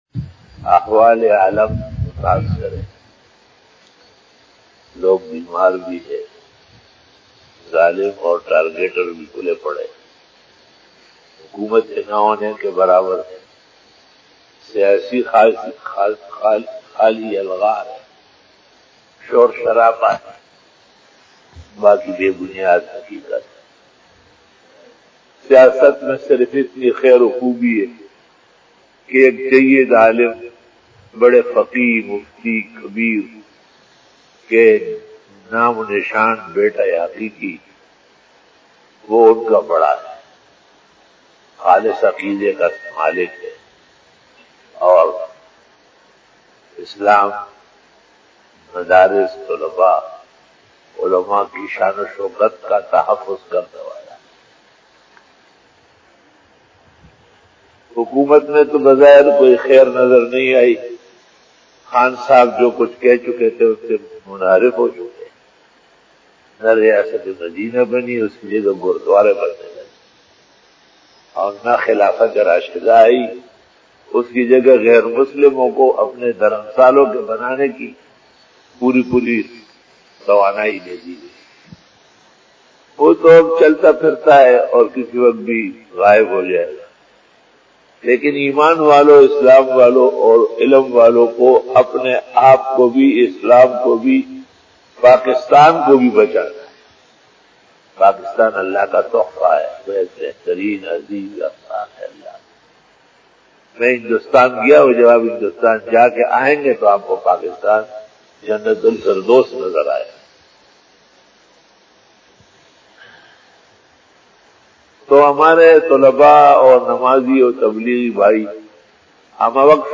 After Namaz Bayan
Fajar bayan